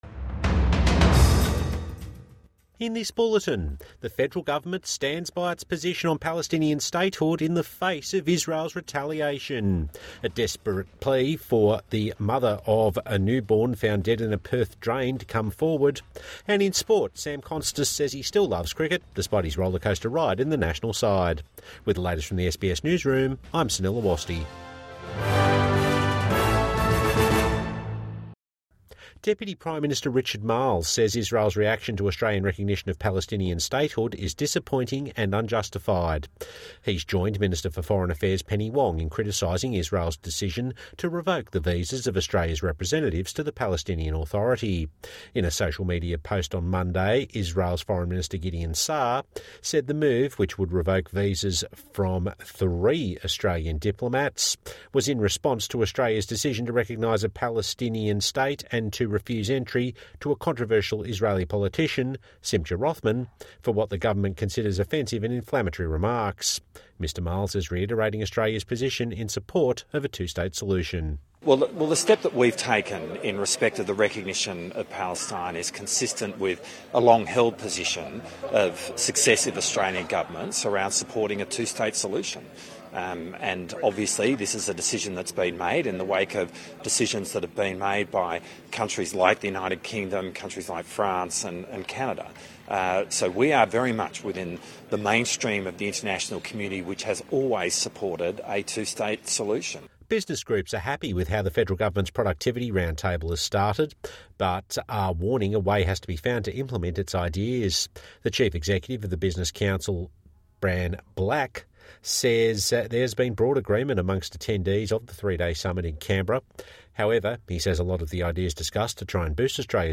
Australia stands by Palestinian decision, despite Israeli retaliation | Evening News Bulletin 19 August 2025